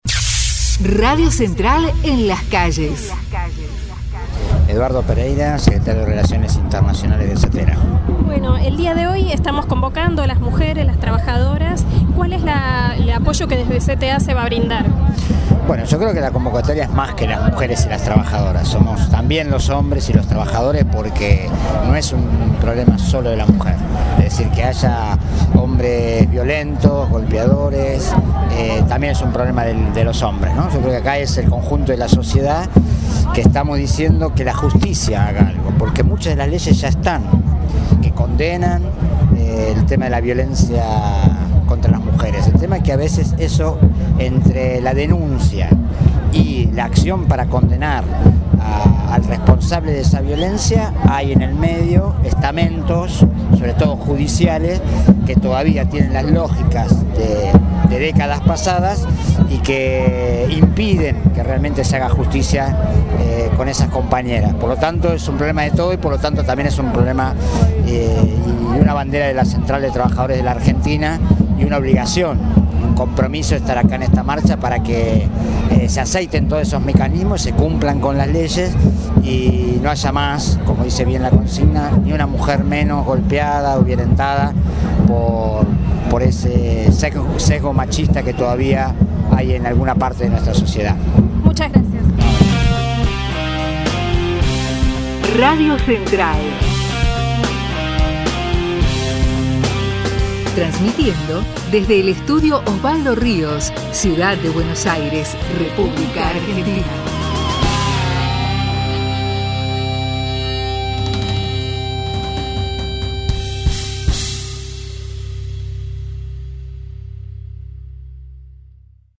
cobertura especial de RADIO CENTRAL